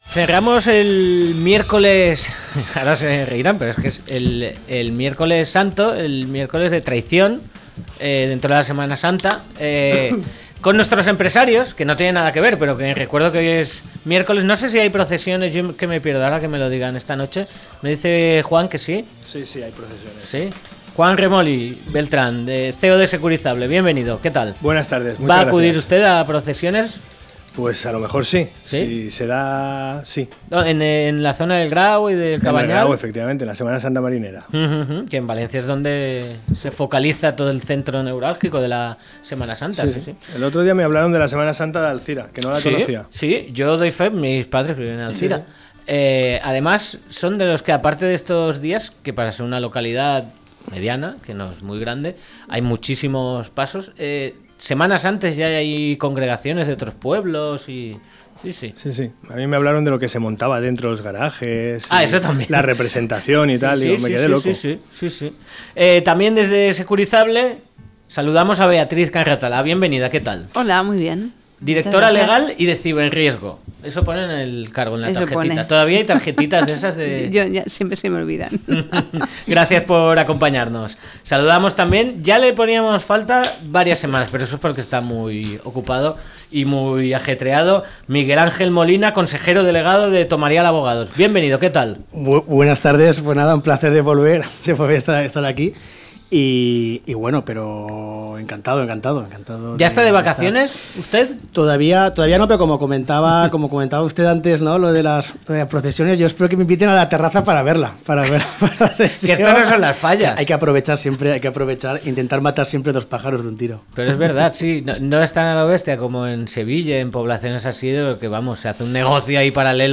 0416-LTCM-TERTULIA.mp3